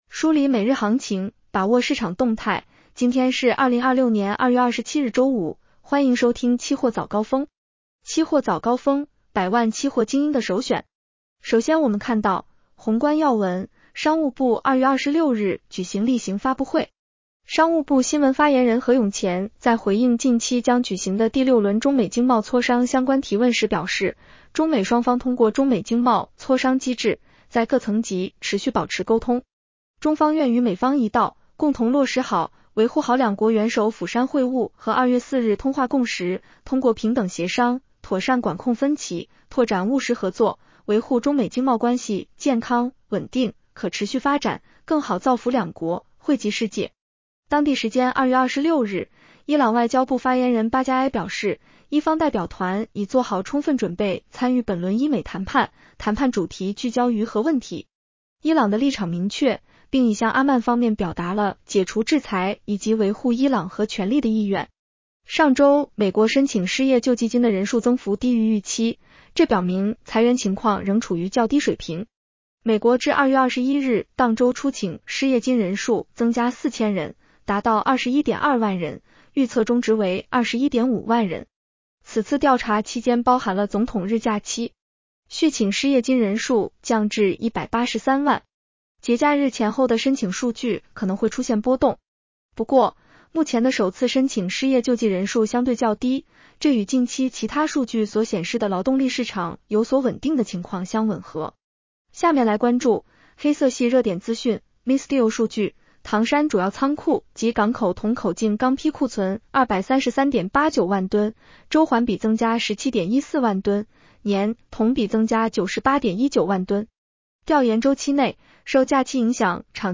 期货早高峰-音频版
期货早高峰-音频版 女声普通话版 下载mp3 热点导读 1.